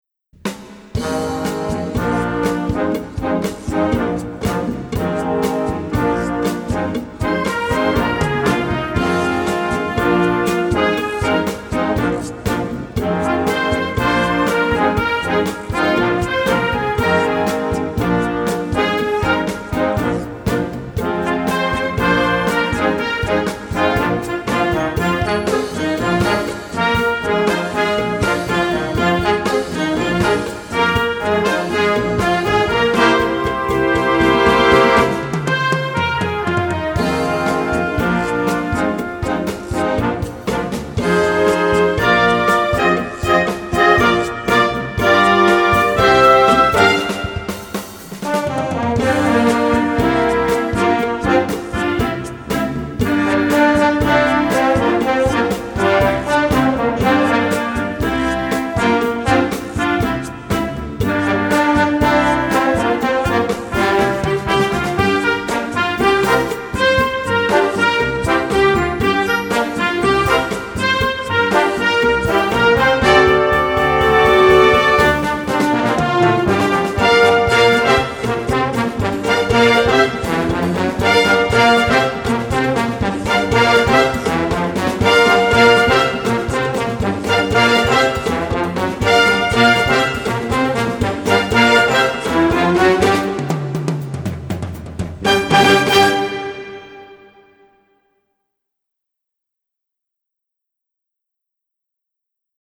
this is Latin/Rock at its best.
Flex-Band